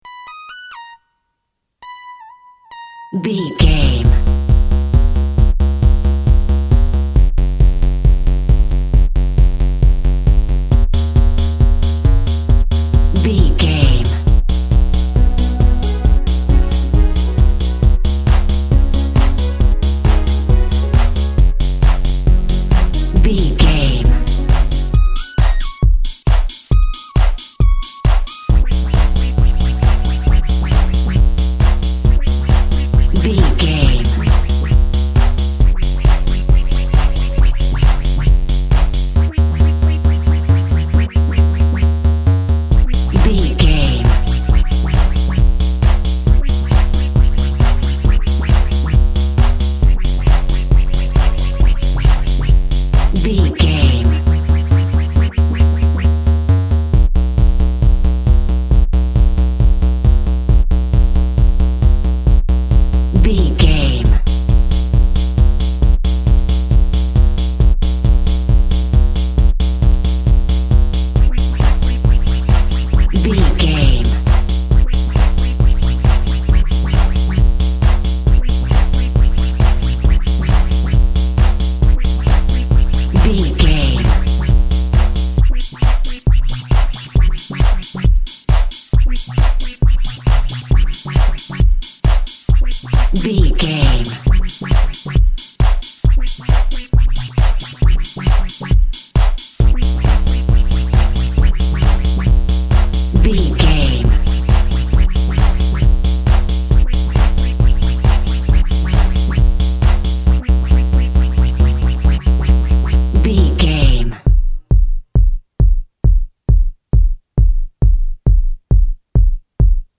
Underground Nightclub Music.
Ionian/Major
energetic
uplifting
futuristic
hypnotic
drum machine
house music
electro
techno
dance instrumentals
synth lead
synth bass
Electronic drums
Synth pads